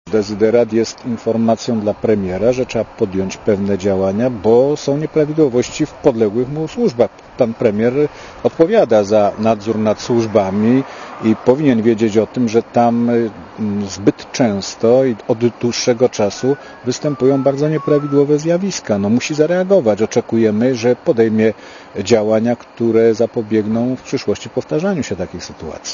Mówi Zbigniew Wasserman